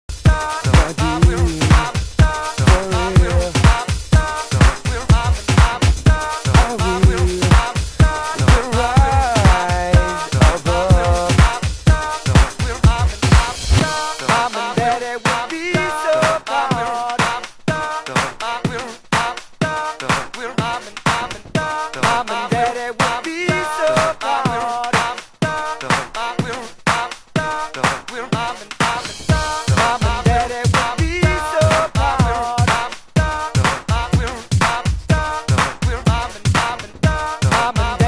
○独自のループ・センスで玄人からの支持も根強い、リミックス・ワーク集！